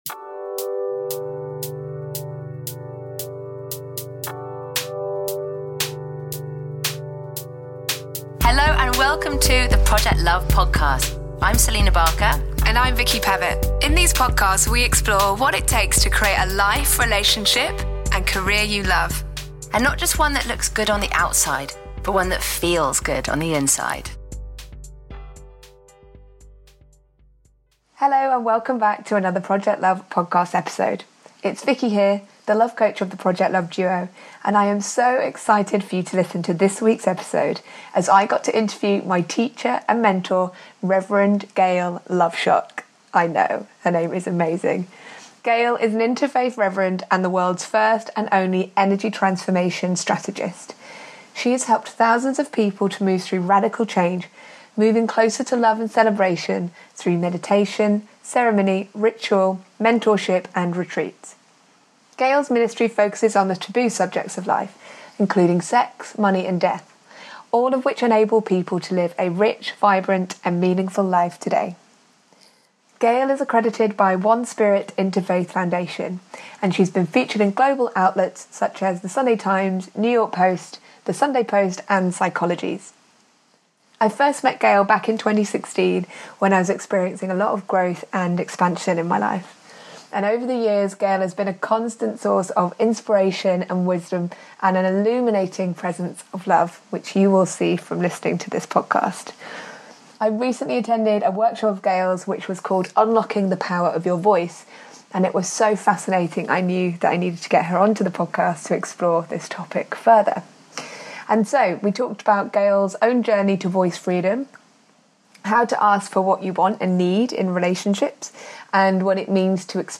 I loved this conversation, I hope you enjoy it too!